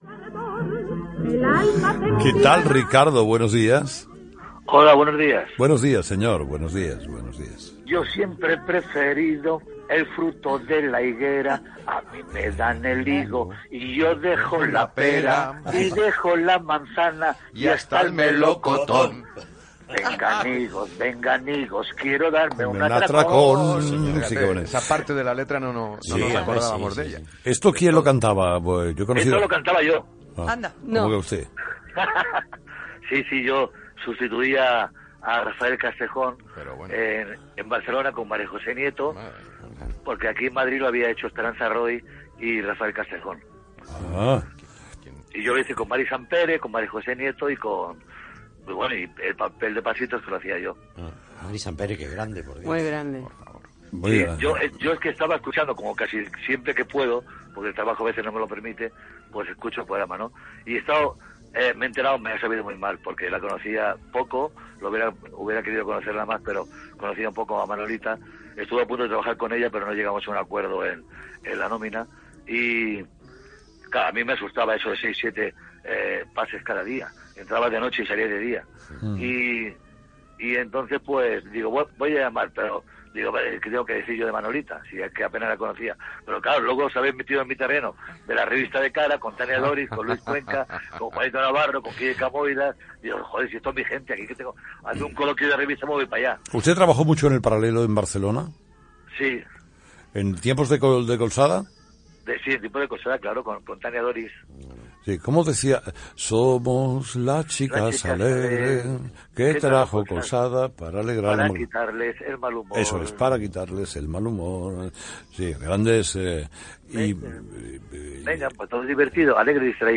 Secció "La Fosforera". Trucada de l'actor Ricardo Arroyo, de la sèrie "La Que se Avecina", per recordar que va treballar al gènere teatral de la revista a Barcelona
Info-entreteniment